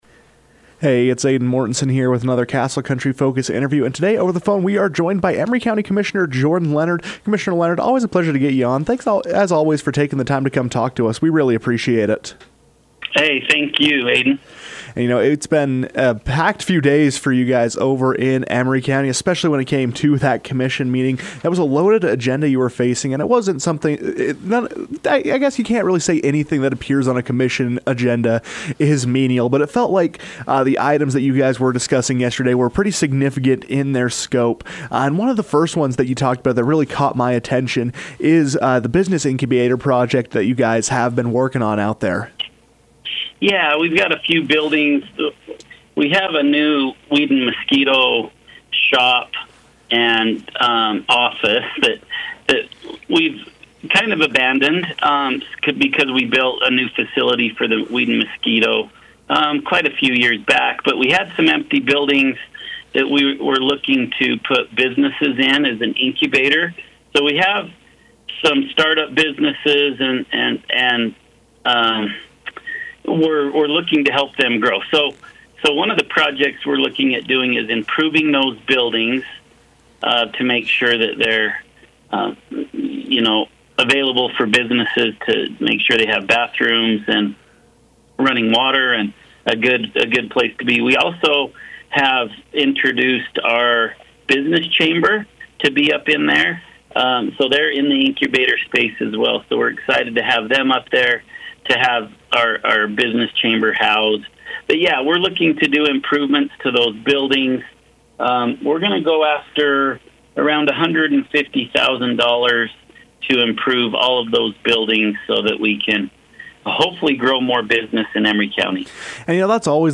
Following a lengthy meeting of the Emery County Board of Commissioners, Commissioner Jordan Leonard sat down with the KOAL newsroom to discuss land use, industry and energy within the county.